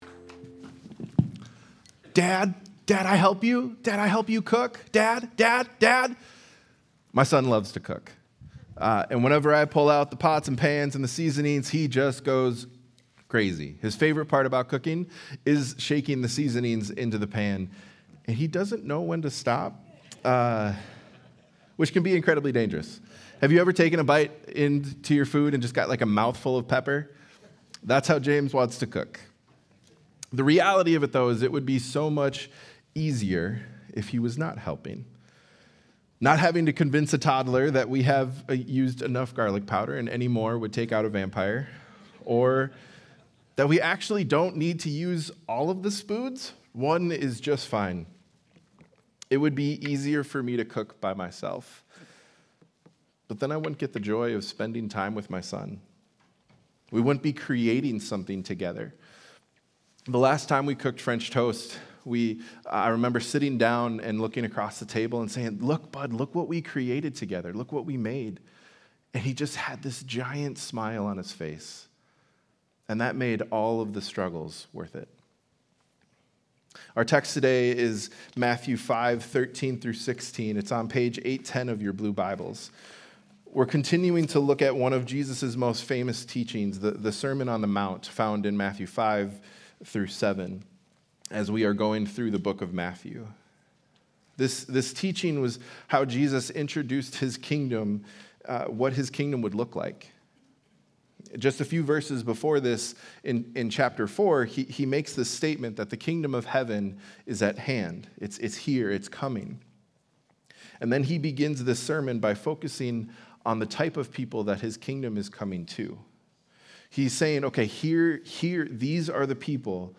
Sunday Sermon: 7-20-25